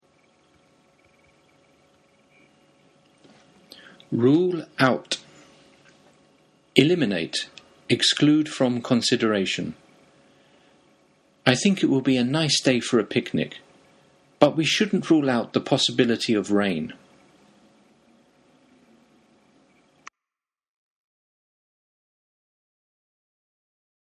マンツーマン英会話レッスンの担当の英語ネイティブによる発音は下記のリンクをクリックしてください。